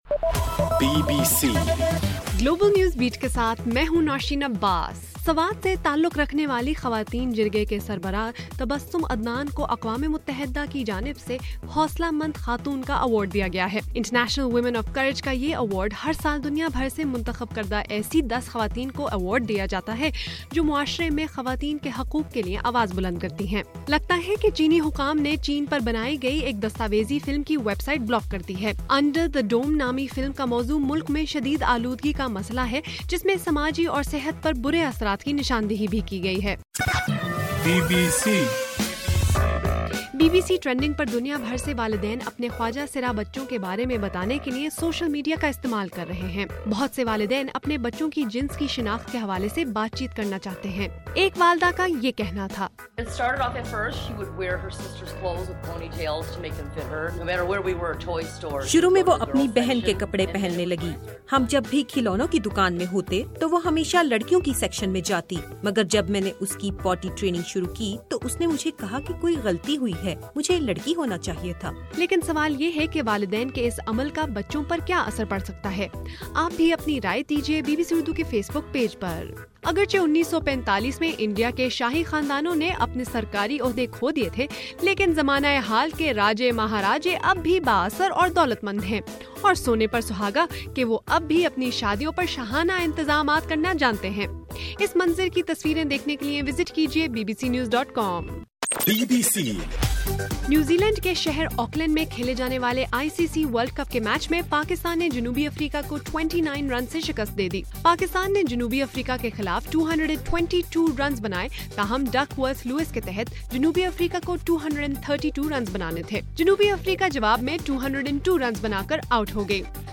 مارچ 7: رات 8 بجے کا گلوبل نیوز بیٹ بُلیٹن